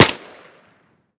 m4a1_sil-2.ogg